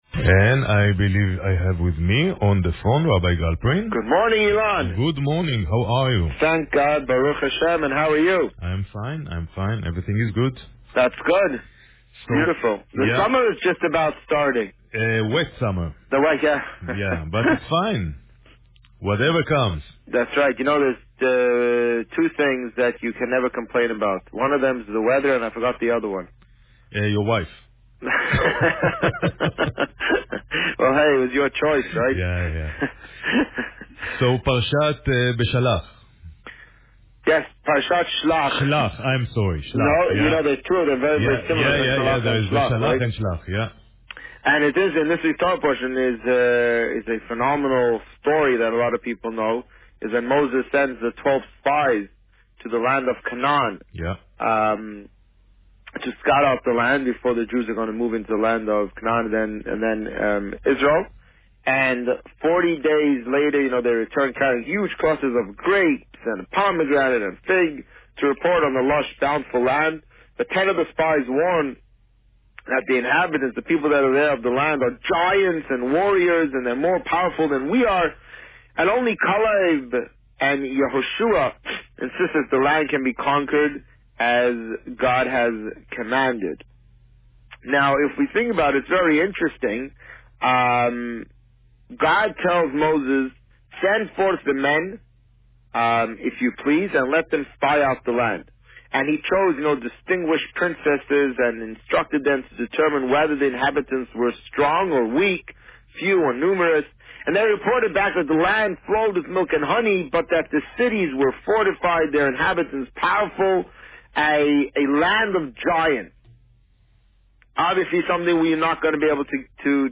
The Rabbi on Radio
Today, the Rabbi spoke about Parsha Shlach.  Listen to the interview